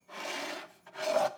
Babushka / audio / sfx / Kitchen / SFX_Cup_Move_01.wav
SFX_Cup_Move_01.wav